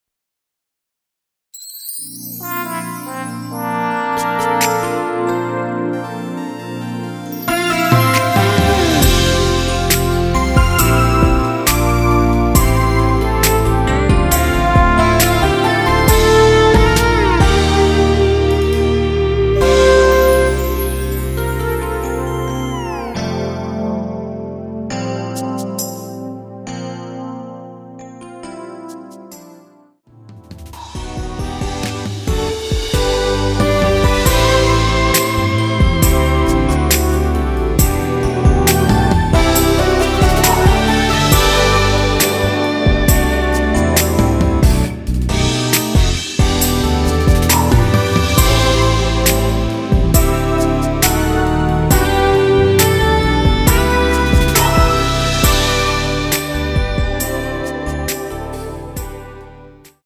원키에서(-2)내린 MR입니다.
앨범 | O.S.T
앞부분30초, 뒷부분30초씩 편집해서 올려 드리고 있습니다.
중간에 음이 끈어지고 다시 나오는 이유는